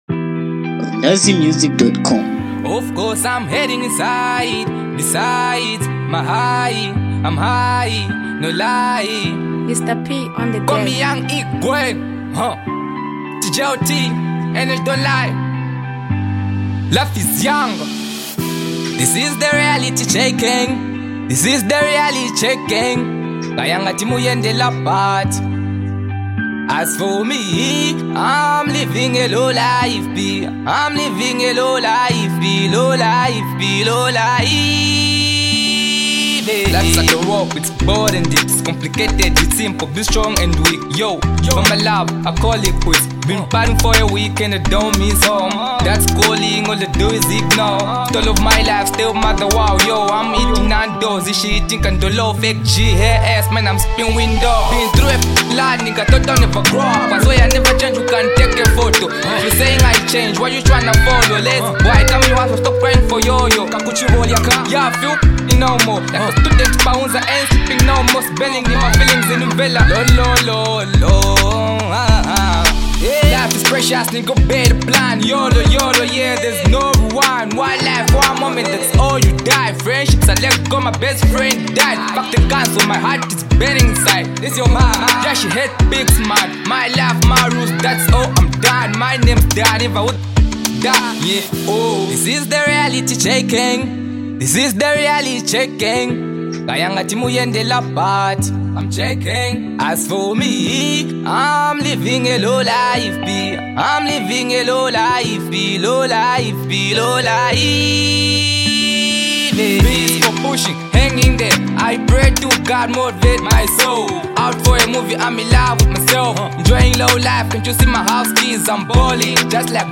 It’s good vibe song for blacks people in Africa.